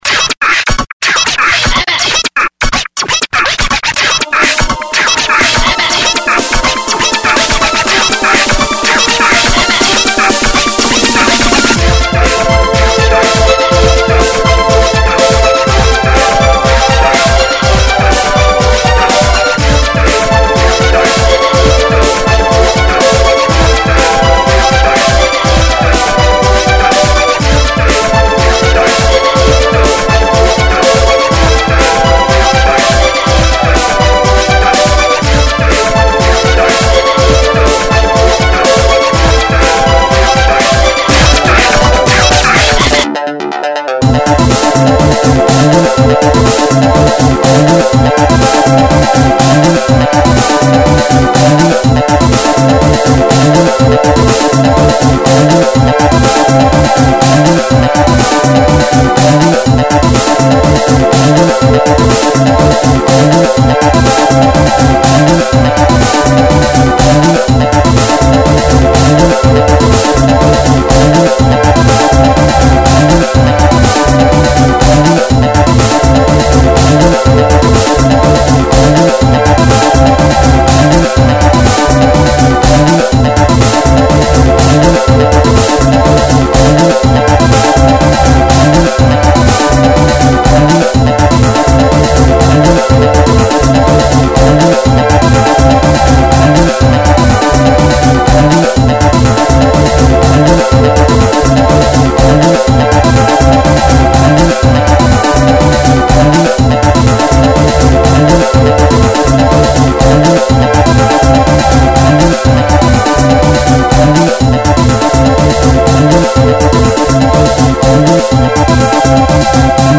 ドラムベースで、比較的テンポの遅い曲です。